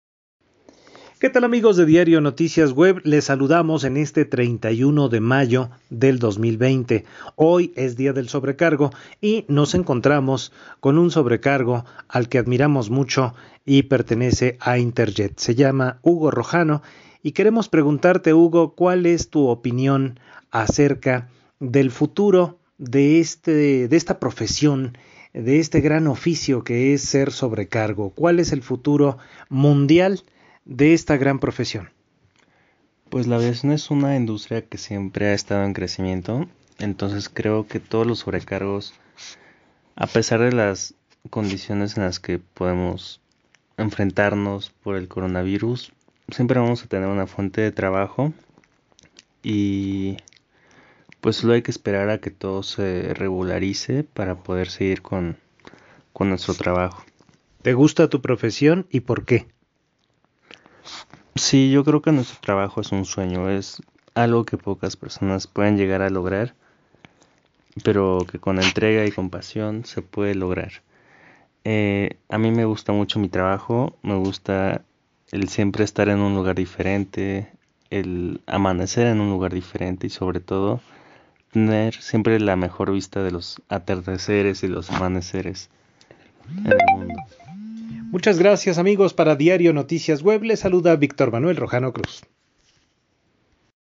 Las dos conversaciones quedaron grabados en dos audios.